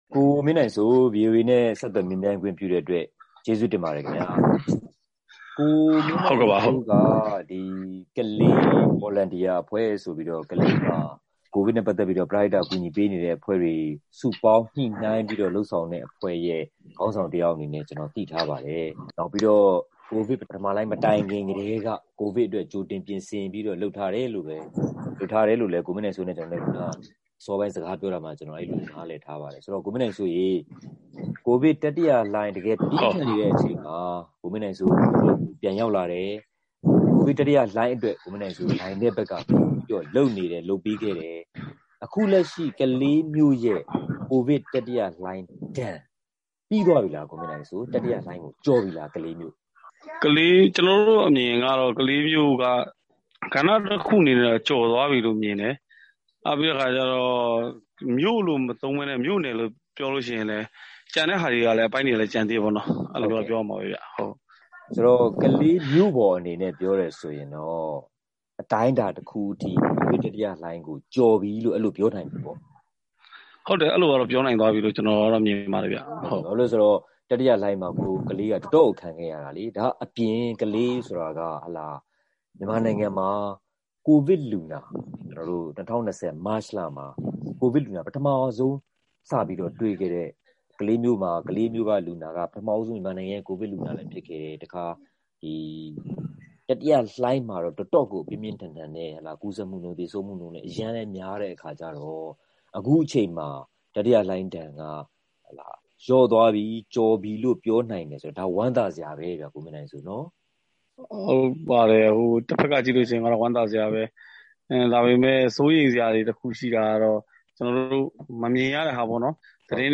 ကိုဗစ်တတိယလှိုင်းဒဏ်ကို အပြင်းအထန်ခံခဲ့ရတဲ့ မြို့တွေထဲက တမြို့ဖြစ်တဲ့ စစ်ကိုင်းတိုင်း၊ ကလေးမြို့မှာ တတိယလှိုင်းဒဏ် ပြေလျော့သက်သာလာပြီလားဆိုတာ မြို့ခံ ပရဟိတလုပ်သားတဦးနဲ့ ဆက်သွယ်မေးမြန်းထားပါတယ်။